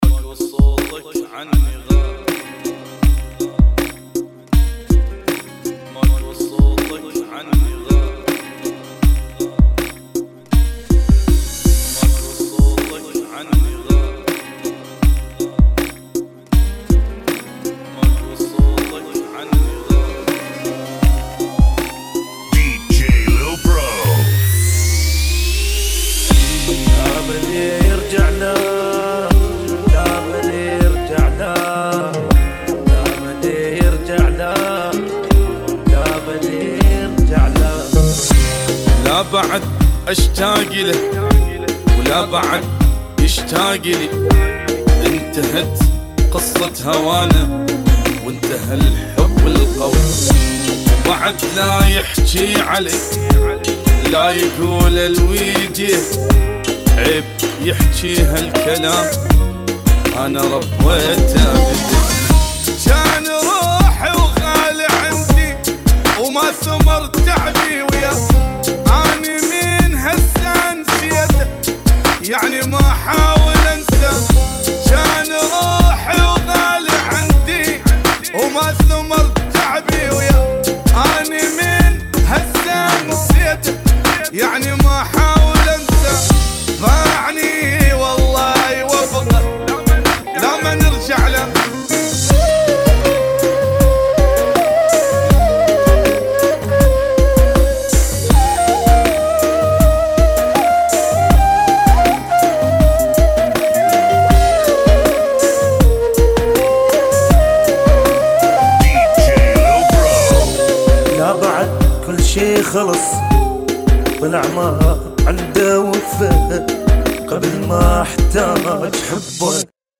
80 Bpm